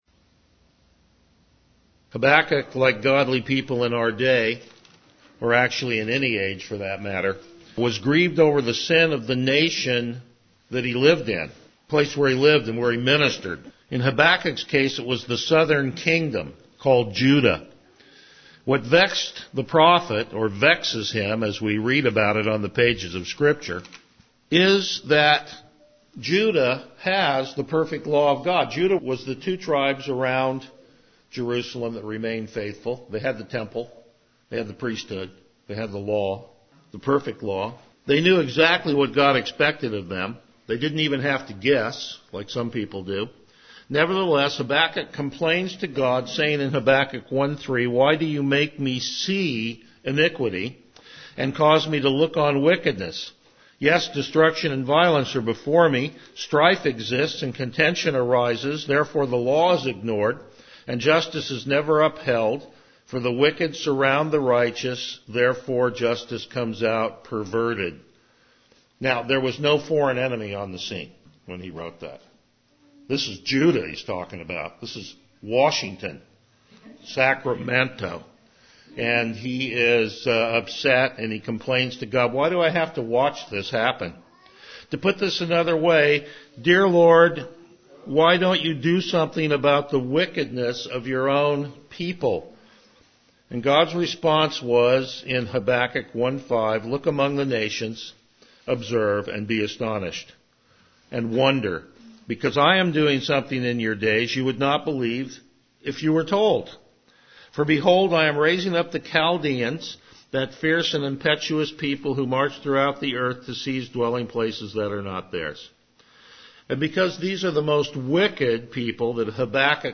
Passage: Habakkuk 3:1-19 Service Type: Morning Worship
Verse By Verse Exposition